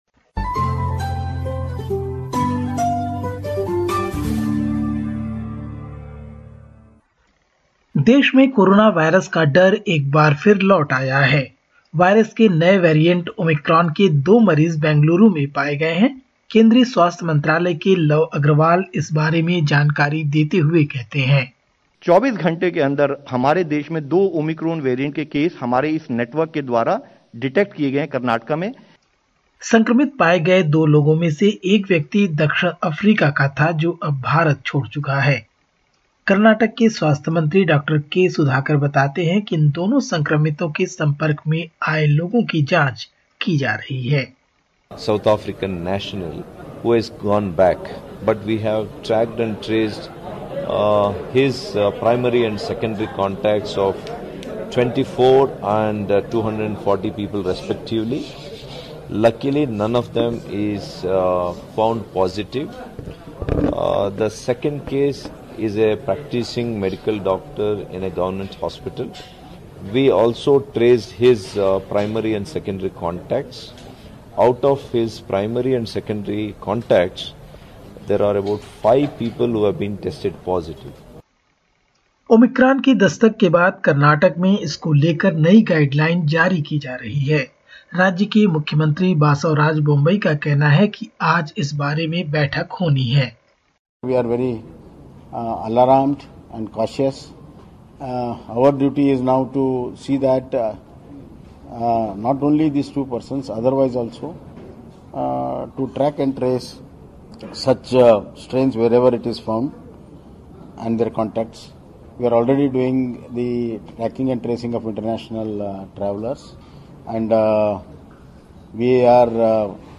In this latest SBS Hindi report from India: Karnataka health authorities trace close contacts of confirmed Omicron cases; Union Health Ministry issues new advisory for returning travellers and more.